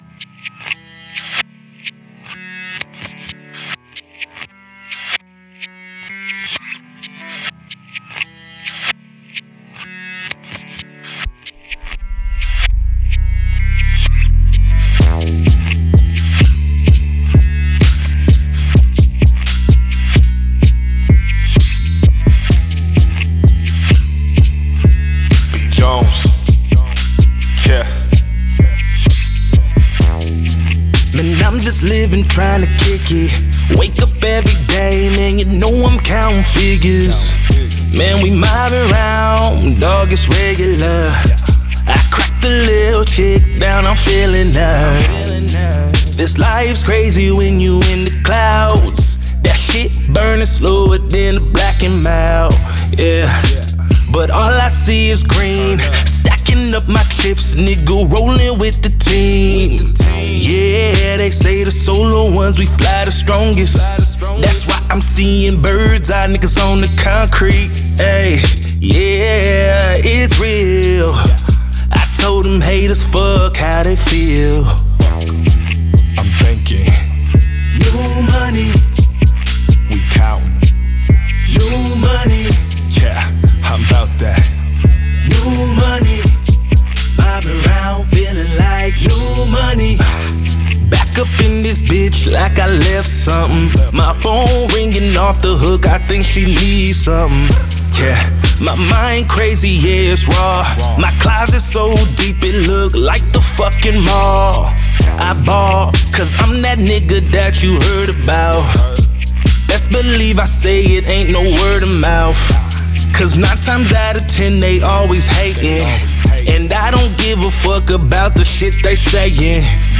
Hip Hop R&B